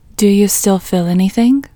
IN Technique First Way – Female English 23